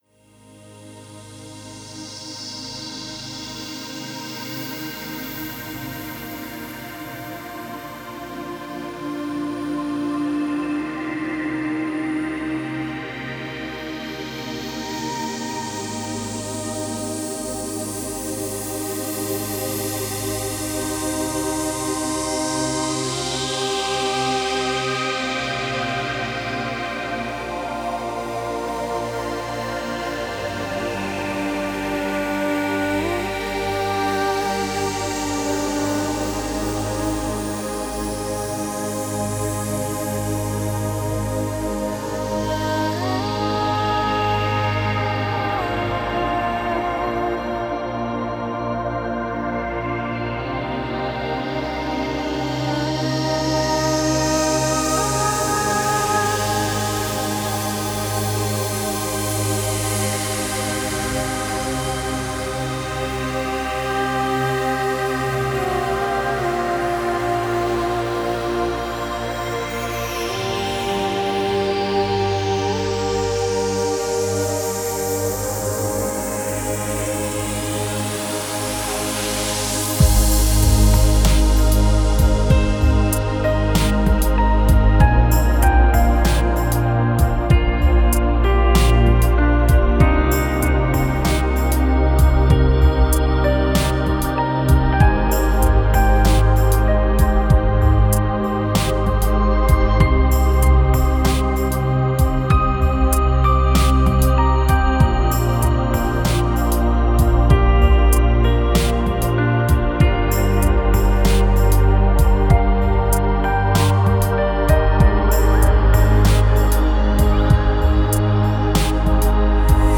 Стиль: Chillout / Lounge / Ambient / Downtempo